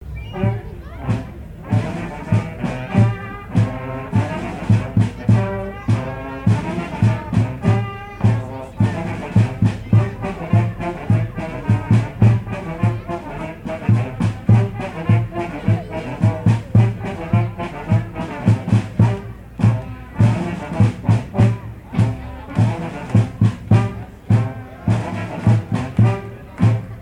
danse : polka piquée
Veillée à Champagné
Pièce musicale inédite